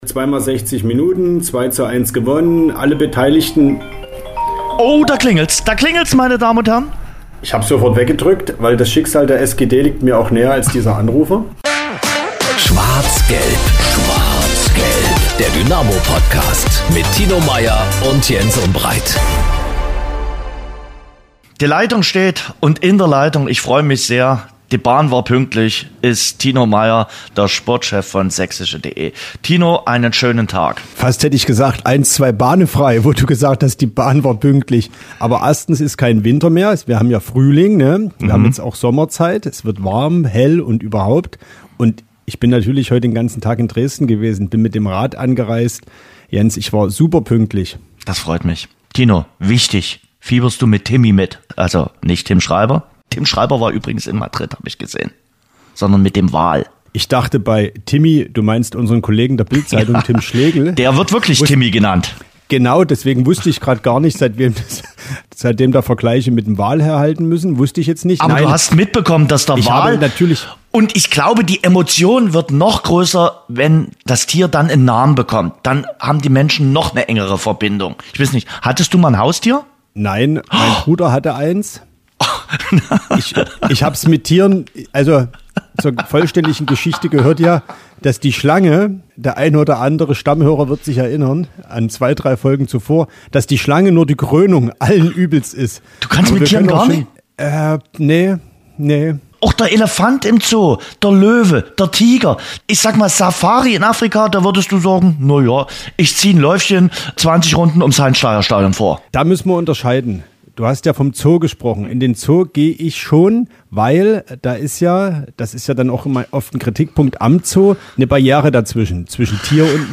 Dazu gibt es Stimmen von Lemmer, Stefan Kutschke und Vincent Vermeij.